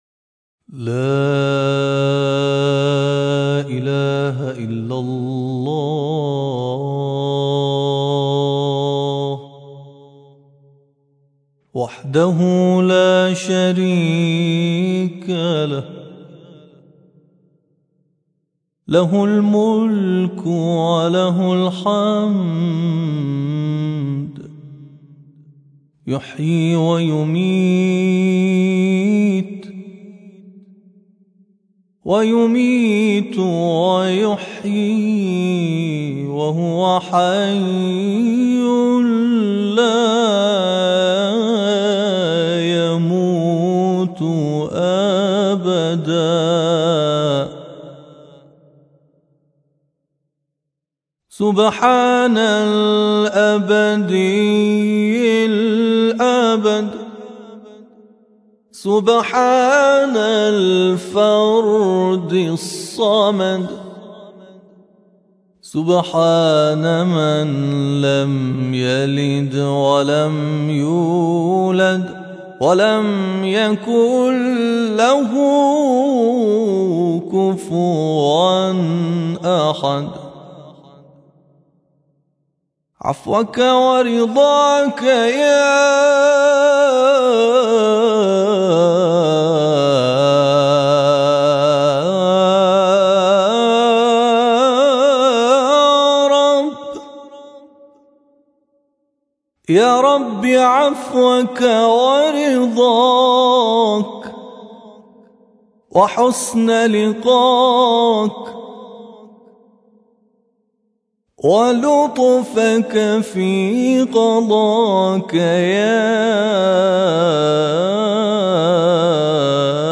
ابتهال